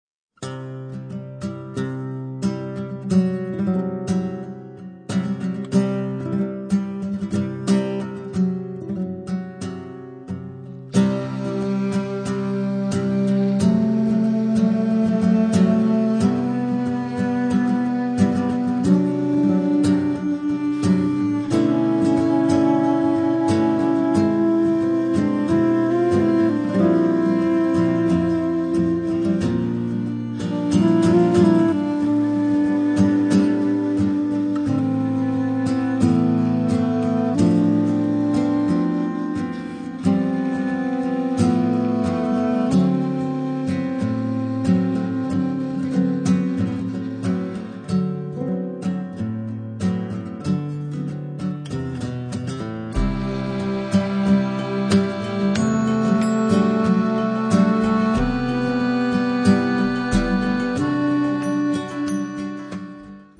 chitarra
fisarmonica
clarinetto e clarinetto basso
contrabbasso
batteria e percussioni